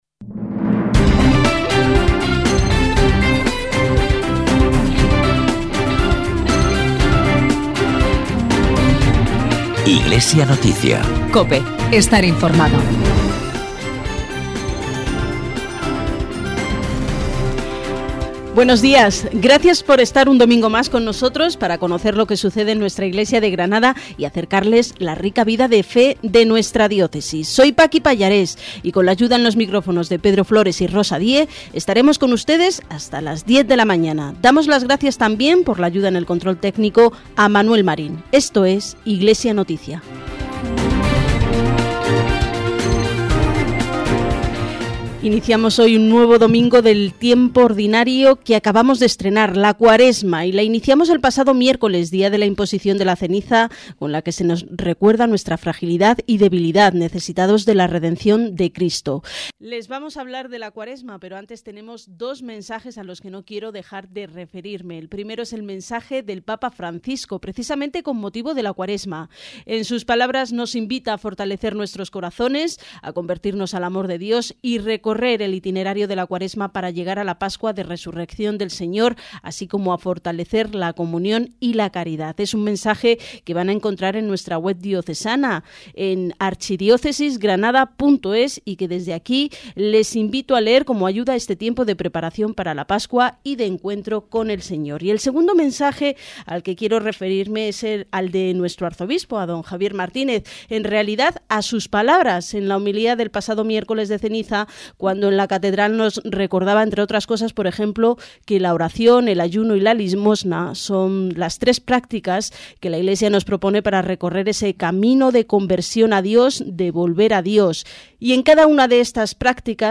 El programa "Iglesia Noticia" es un informativo realizado por el Secretariado de Medios de Comunicación Social del Arzobispado de Granada, con el objetivo de informar sobre la actualidad de la Archidiócesis cada semana, a través de COPE Granada, los domingos a las 9:45 horas.